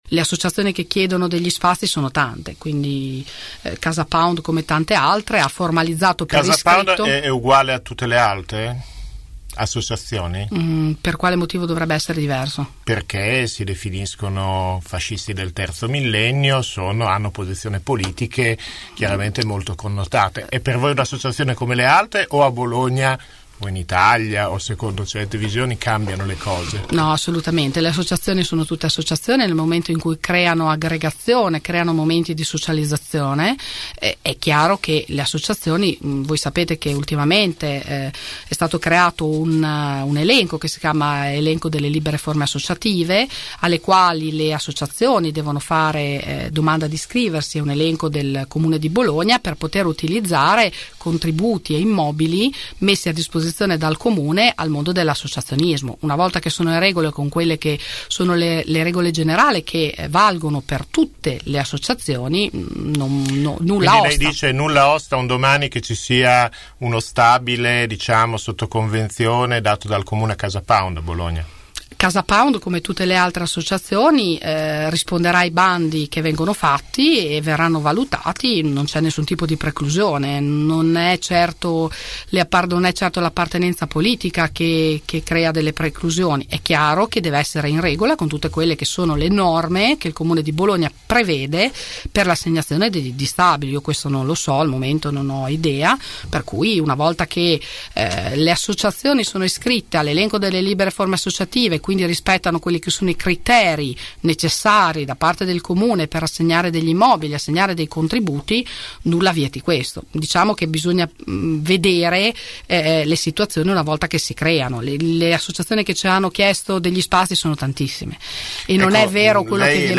Ospite nei nostri studi, l’assessore fa intendere che Casapound, qualora partecipasse ai bandi per le libere forme associative, potrebbe concorrere all’assegnazione di spazi e contributi pubblici.
Ascolta l’assessore Luisa Lazzaroni.